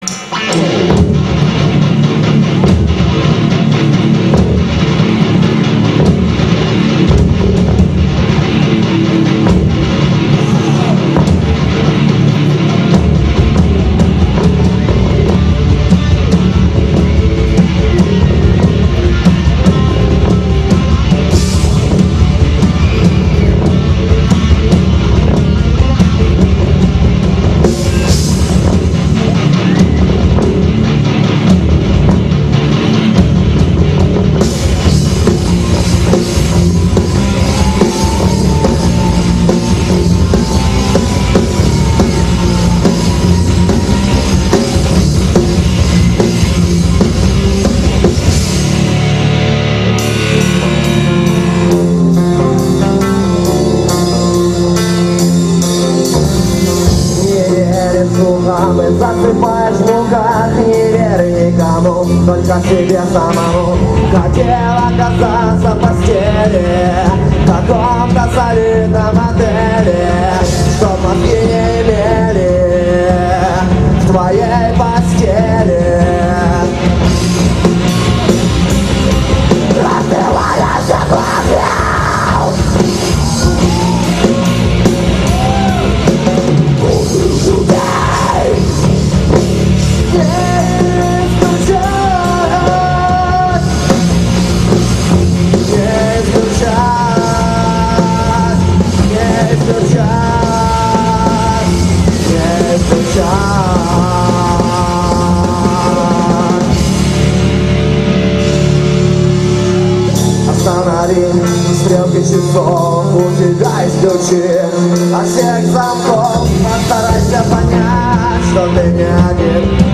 Приветствуем Вас на сайте нашей рок-группы!